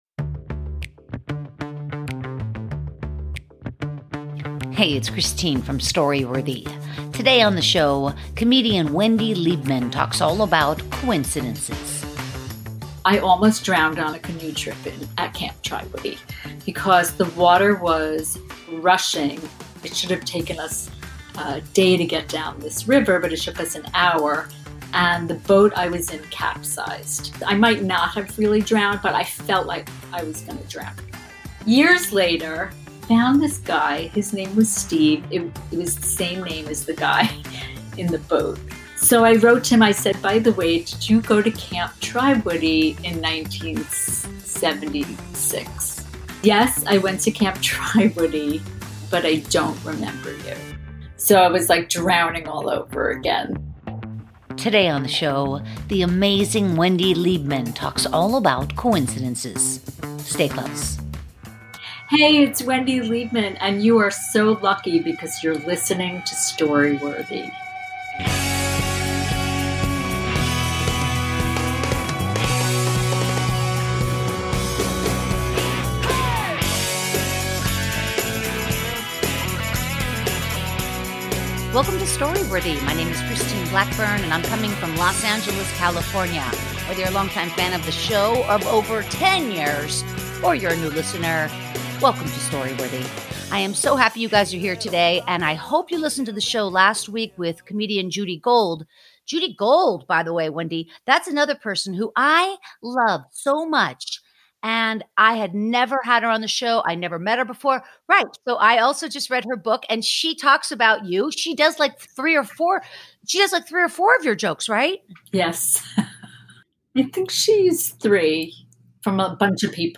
637 - Coincidences with Comedian Wendy Liebman